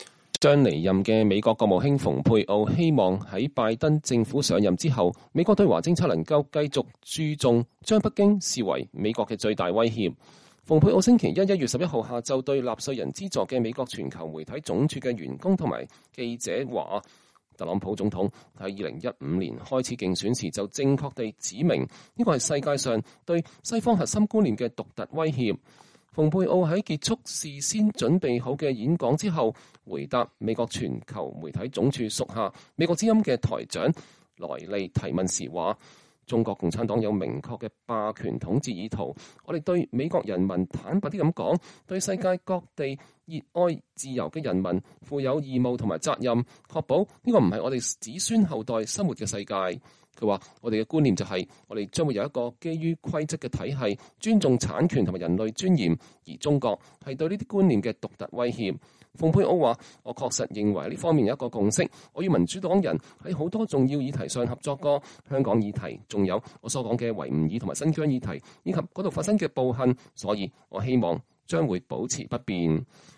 國務卿蓬佩奧在美國之音總部發表講話(2021年1月11日)